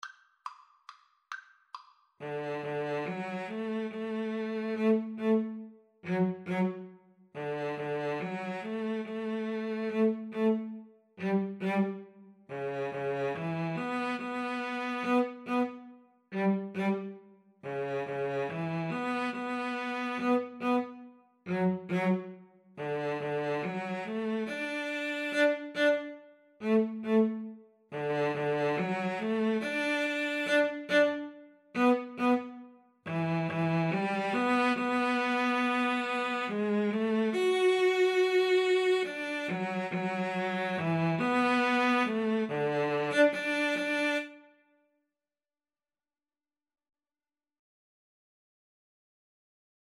Free Sheet music for Cello Duet
D major (Sounding Pitch) (View more D major Music for Cello Duet )
3/4 (View more 3/4 Music)
=140 Slow one in a bar
Classical (View more Classical Cello Duet Music)